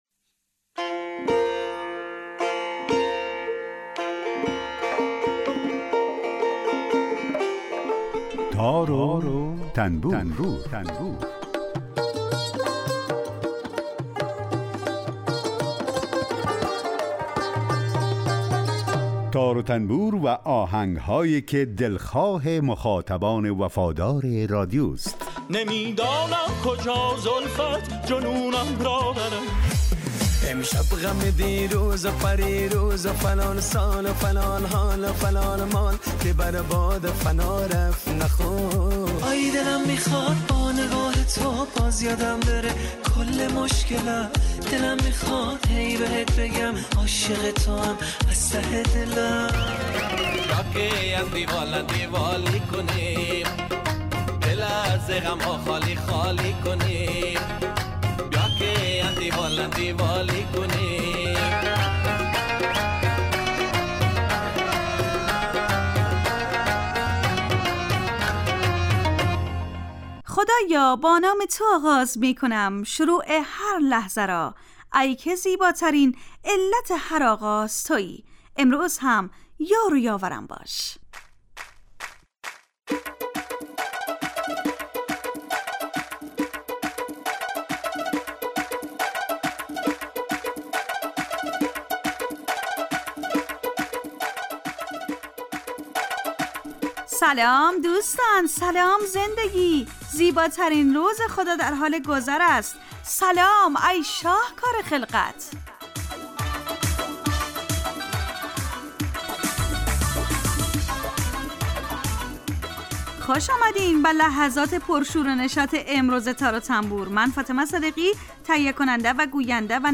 برنامه تار و تنبور هر روز از رادیو دری به مدت 30 دقیقه برنامه ای با آهنگ های درخواستی شنونده ها کار از گروه اجتماعی رادیو دری.
یک قطعه بی کلام درباره همون ساز هم نشر میکنیم